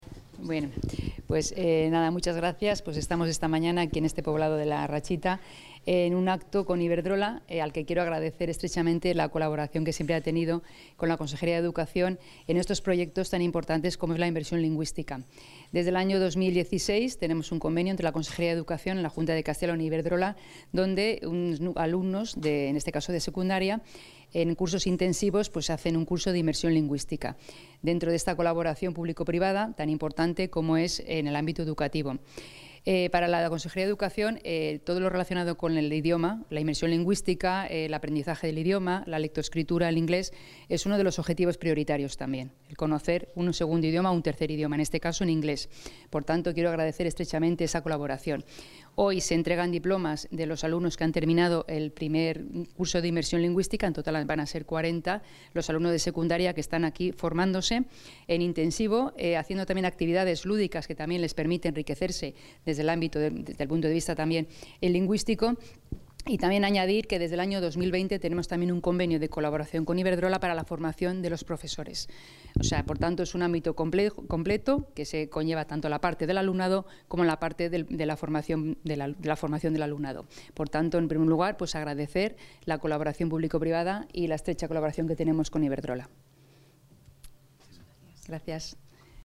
Audio consejera de Educación.